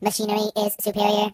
project_files/HedgewarsMobile/Audio/Sounds/voices/Robot/Amazing.ogg